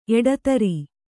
♪ eḍatari